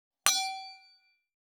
310ステンレスタンブラー,シャンパングラス,ウィスキーグラス,ヴィンテージ,ステンレス,金物グラス,
効果音厨房/台所/レストラン/kitchen食器
効果音